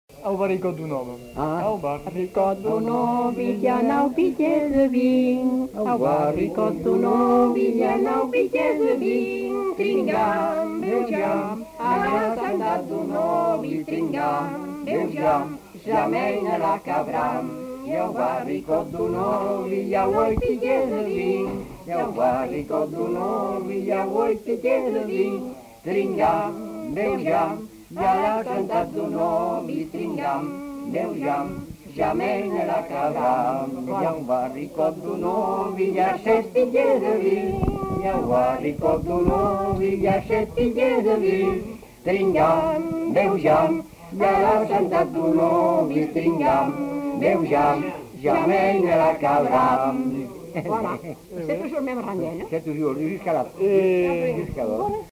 Aire culturelle : Grandes-Landes
Lieu : Luxey
Genre : chant
Type de voix : voix mixtes
Production du son : chanté
Danse : rondeau